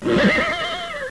horse_neigh.aif